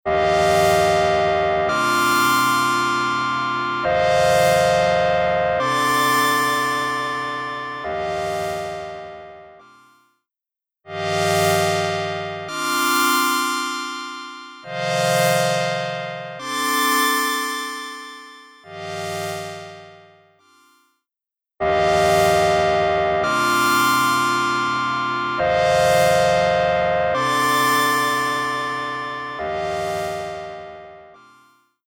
パワフルなディストーション＆サチュレーション・エンジン
CrushStation | Synth Pad | Preset: Old Tube Compressor
CrushStation-Eventide-Synth-Pad-Old-Tube-Compressor.mp3